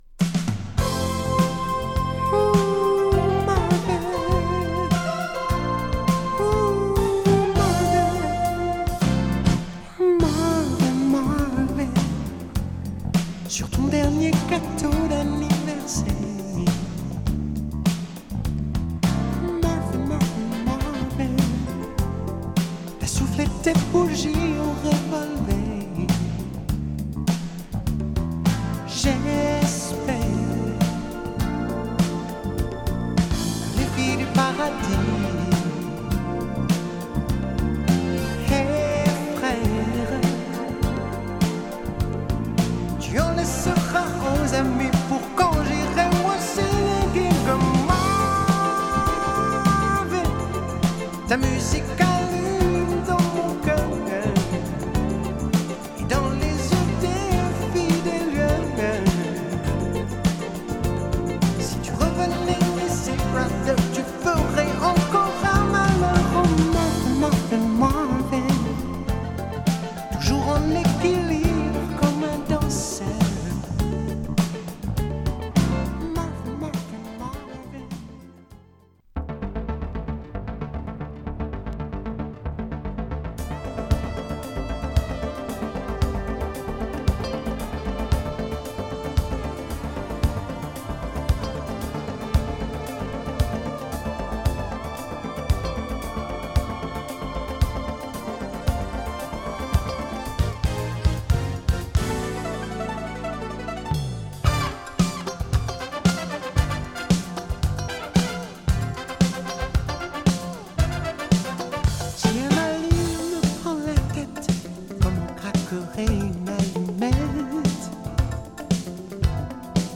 仏産80'sソウルフル・シンガー♪
メロウなジャジーソウル